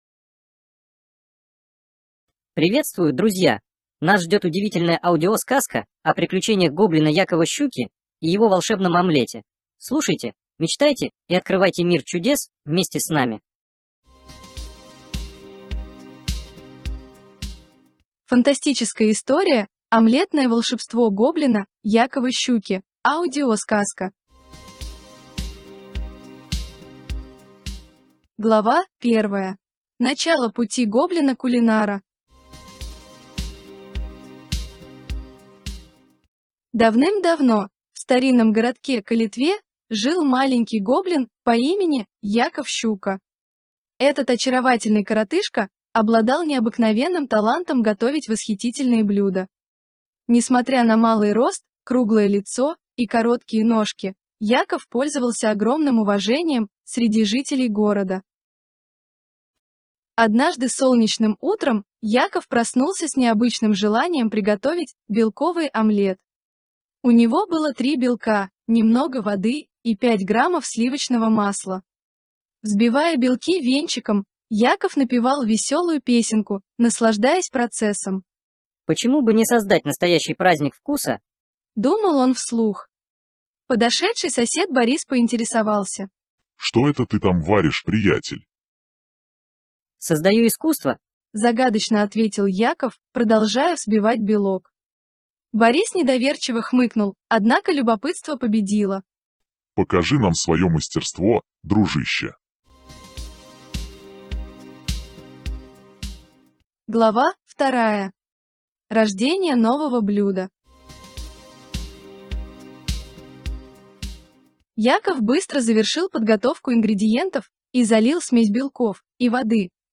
Нас ждёт удивительная аудиосказка о приключениях гоблина Якова Щуки и его волшебном омлете.